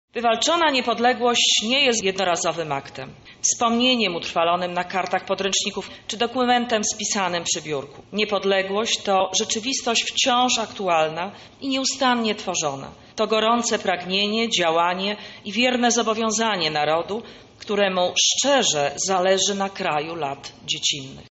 Uroczysta sesja Rady Miasta rozpoczęła dzisiejsze obchody. Na sesji nastąpiło odczytanie odezwy Komitetu Honorowego Obchodów Narodowego Święta Niepodległości.
Bo ojczyzna, to jak pisał Tadeusz Różewicz, kraj dzieciństwa, miejsce urodzenia, to jest ta mała,najbliższa ojczyzna: miasto, miasteczko, wieś – czytała w odezwie wojewoda lubelska Jolanta Szołno – Koguc.